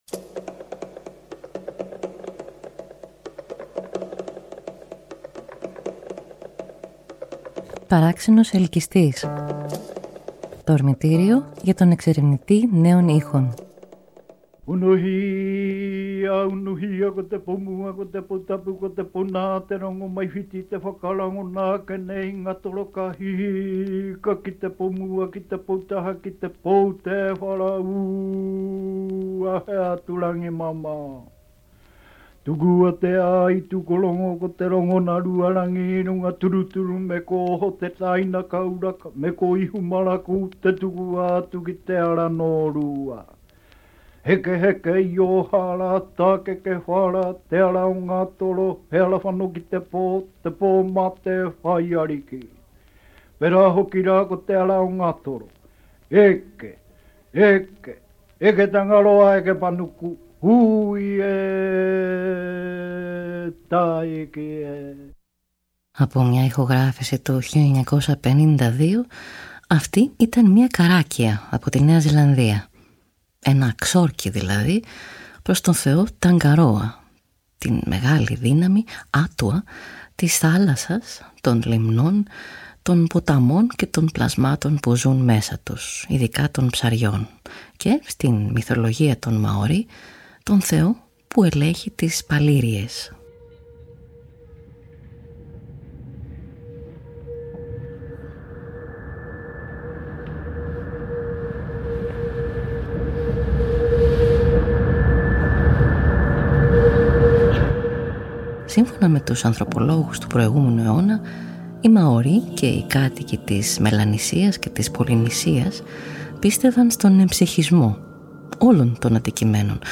Ακούγοντας: Ξόρκια προς τον θεό της θάλασσας Tangaroa από τη Νέα Ζηλανδία.
Ένα παραδοσιακό νανούρισμα από τους Ινδιάνους Iroquis του Καναδά, οι οποίοι πίστευαν στην «Orenda» – μια έννοια παρεμφερή με το Μάνα.